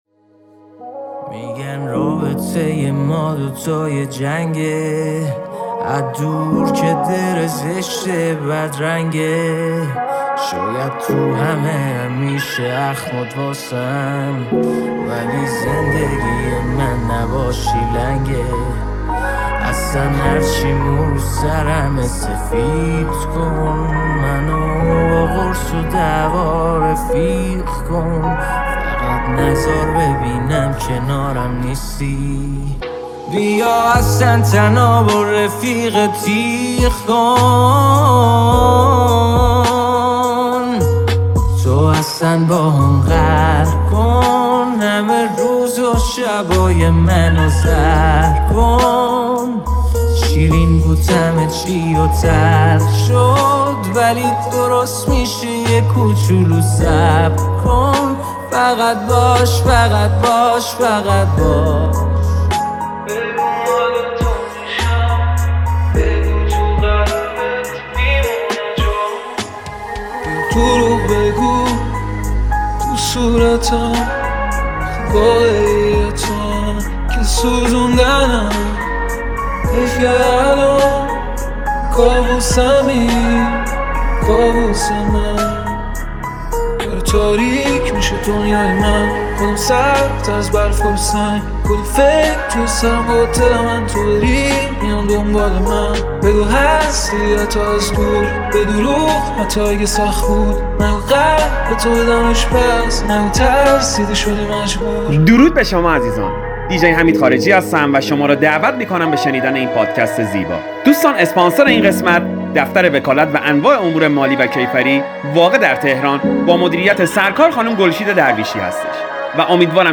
میکس عاشقانه برای اسفند ماهی‌ها